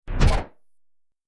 دانلود آهنگ تصادف 31 از افکت صوتی حمل و نقل
جلوه های صوتی
دانلود صدای تصادف 31 از ساعد نیوز با لینک مستقیم و کیفیت بالا